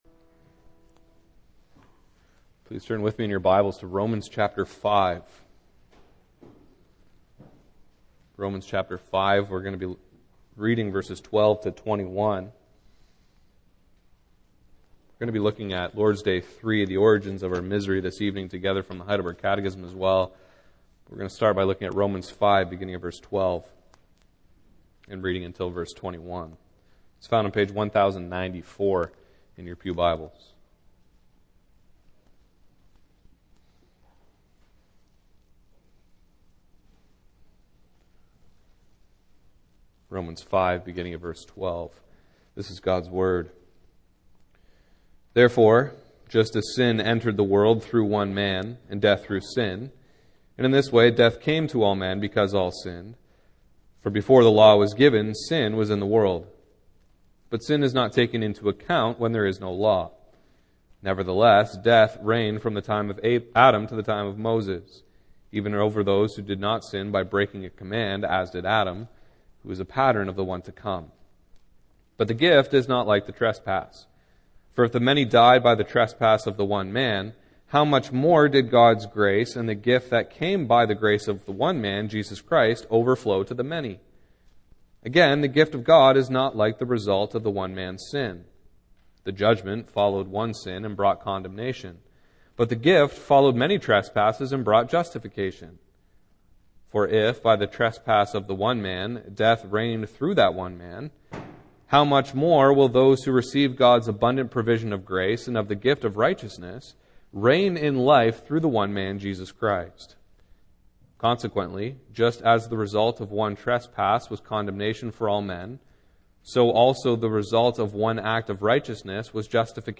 Series: Single Sermons Passage: Romans 5:12-21 Service Type: Evening